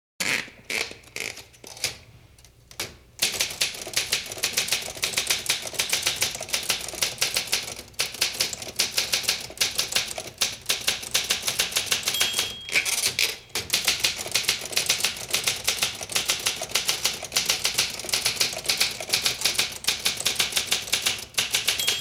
Звуки печатания
Быстрая печать руками на печатной машинке